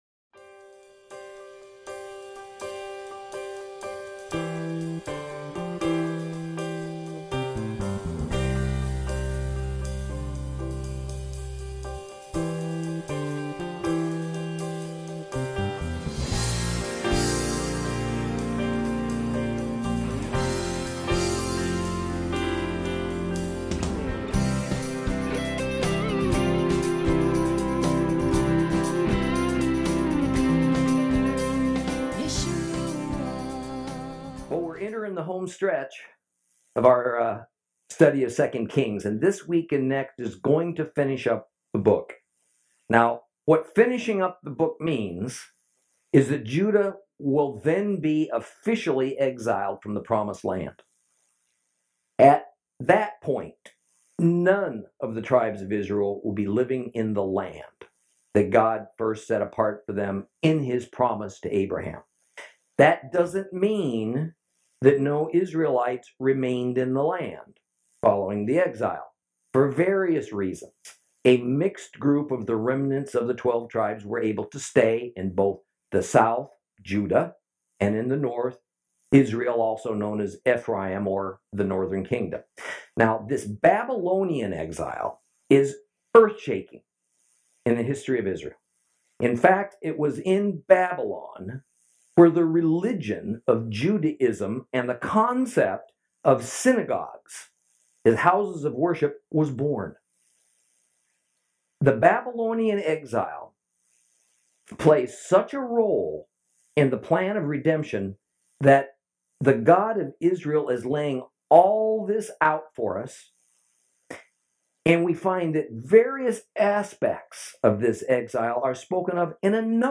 Lesson 39 – 2 Kings 24 and 25